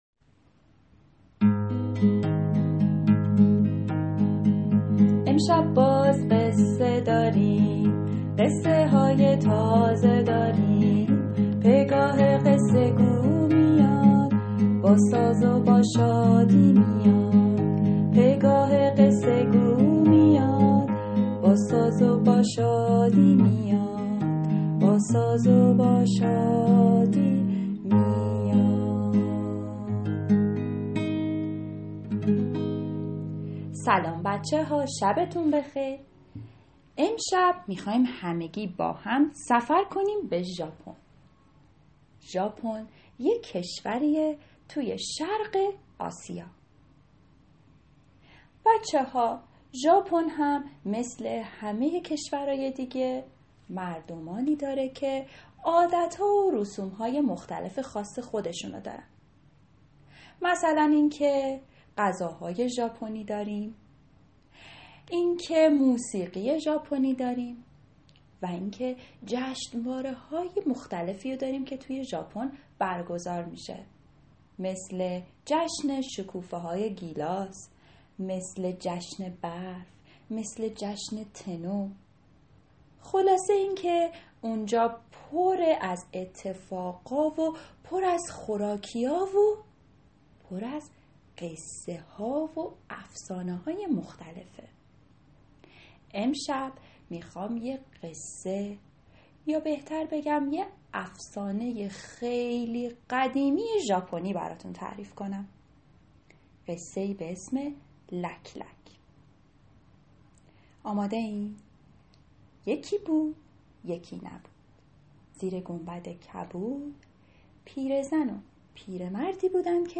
قصه صوتی کودکان دیدگاه شما 1,831 بازدید